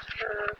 All of the investigations were conducted between 12 midnight and 3am over a two day period.
EVP's